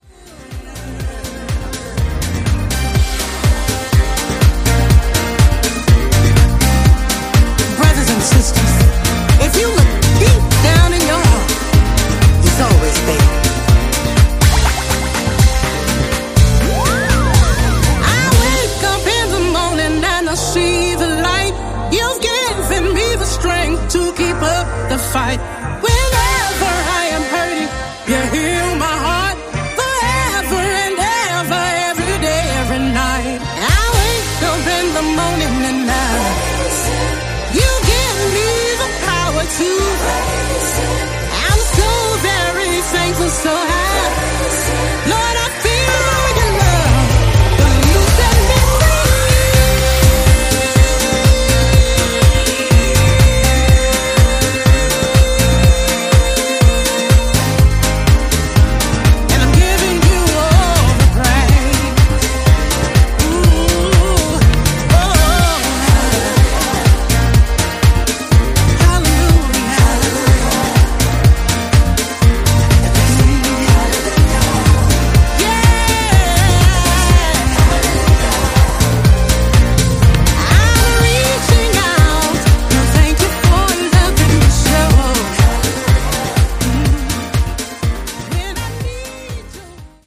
vocal powerhouse
disco driven gospel stormer